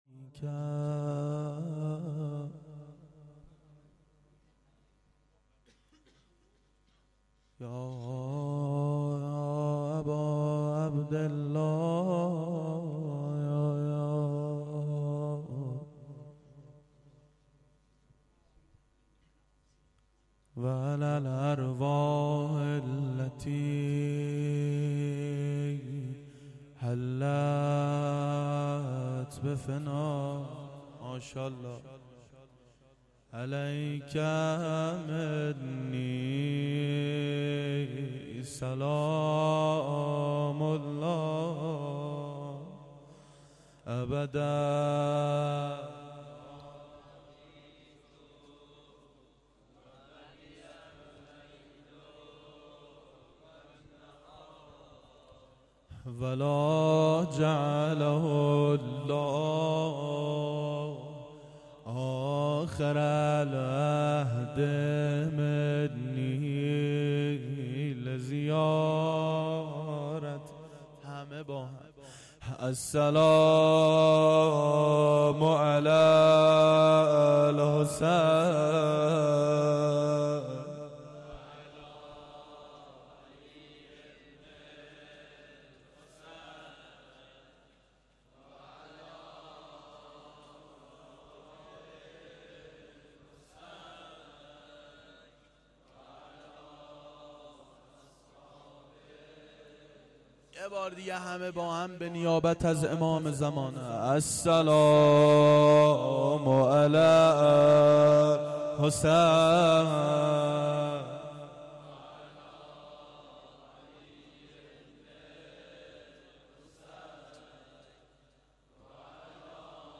روضه
شب سوم محرم 88 گلزار شهدای شهر اژیه
روضه-شب-سوم-محرم.mp3